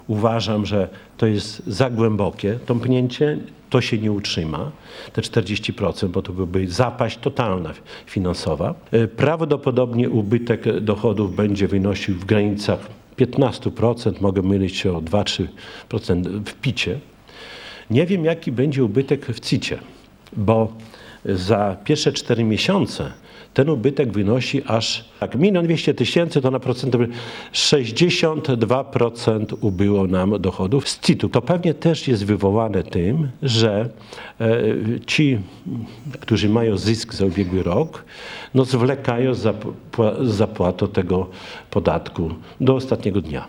– W związku z tym o konkretach będzie można mówić w lipcu – zapowiedział na ostatniej sesji Rady Miejskiej Czesław Renkiewicz, prezydent Suwałk.